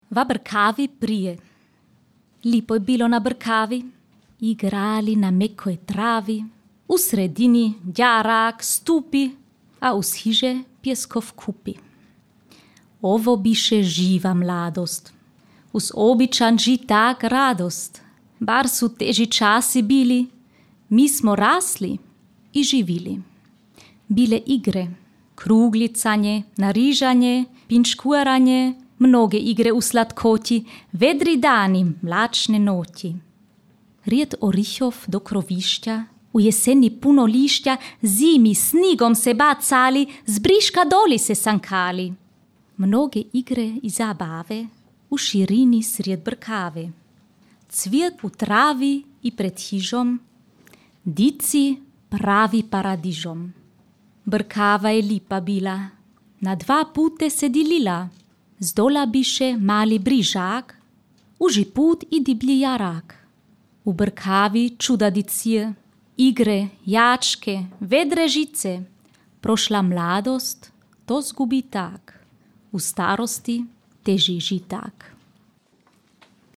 čita: